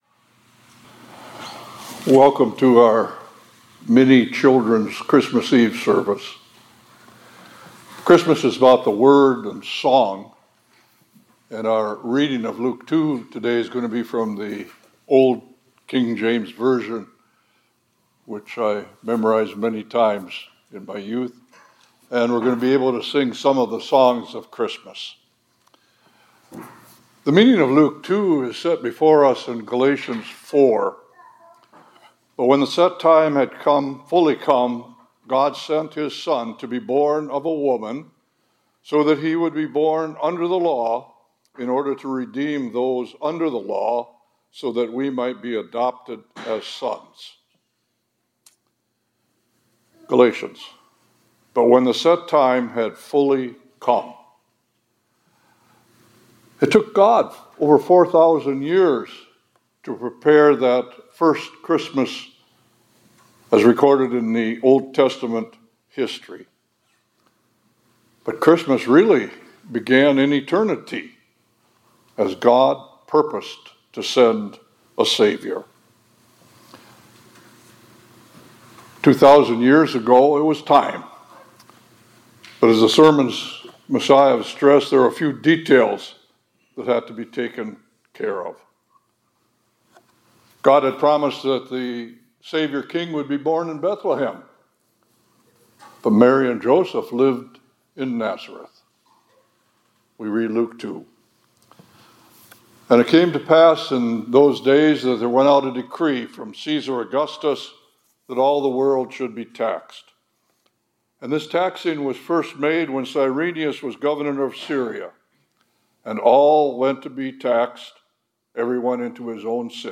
2024-12-26 ILC Chapel — The Christmas Story